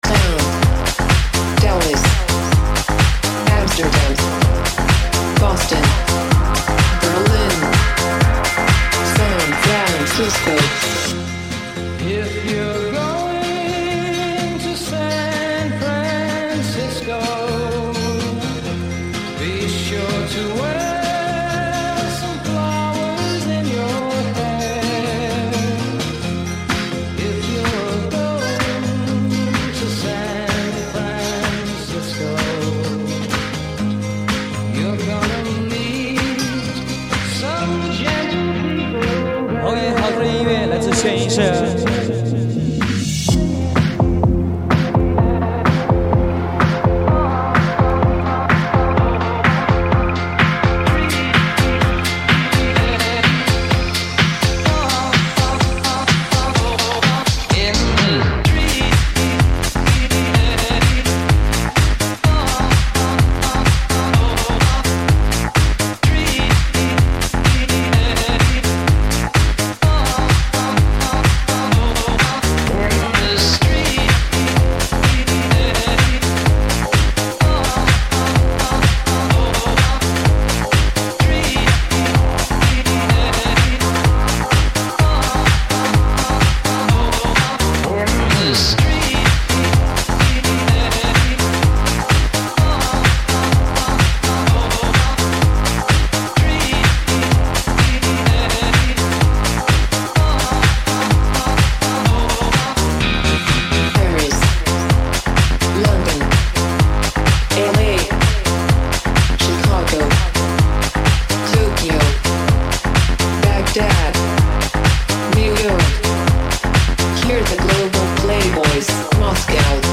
串烧